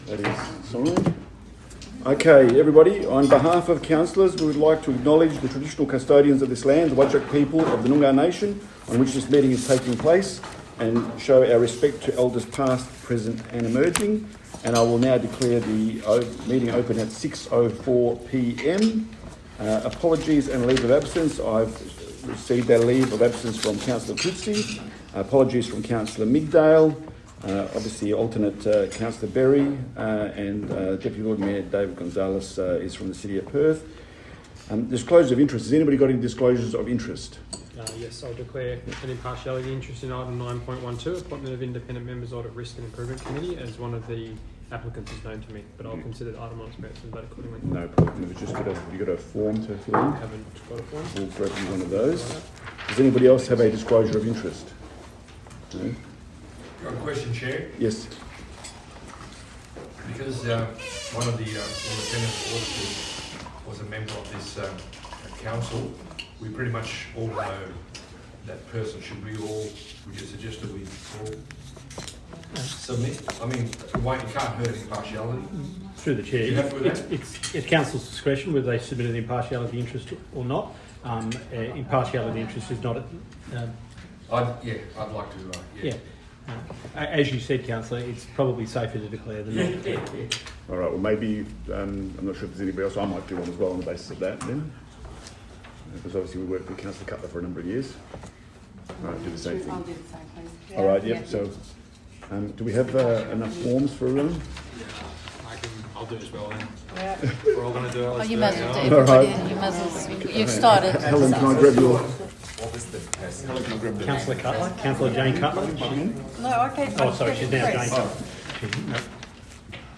Play Audio - 11/12/2025 AUDIO - Ordinary Meeting of Council